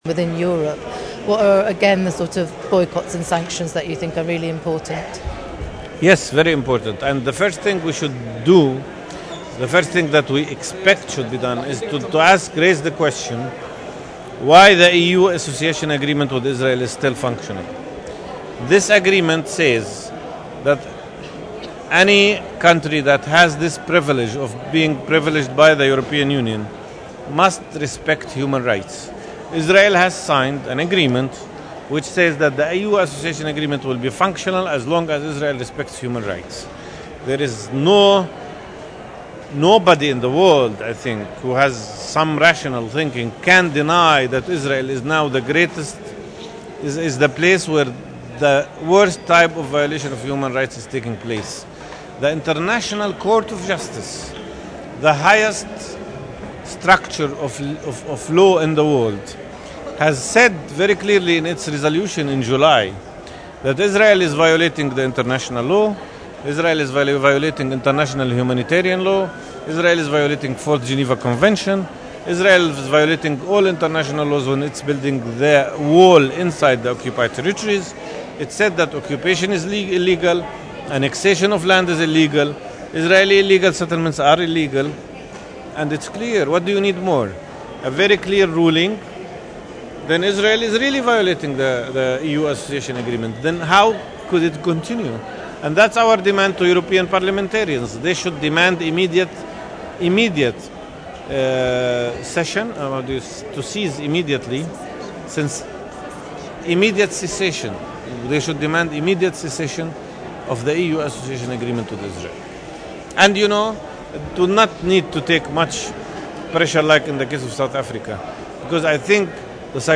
Download: Interview - mp3 1.4M